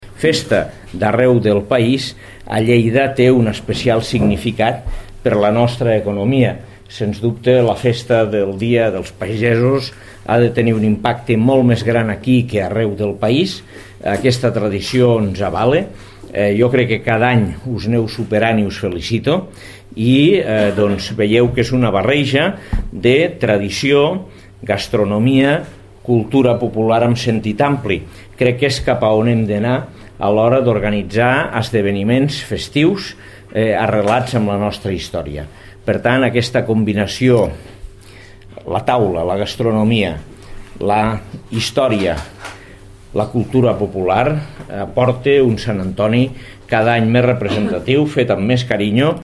tall-de-veu-de-lalcalde-angel-ros-sobre-la-festivitat-de-sant-antoni-abat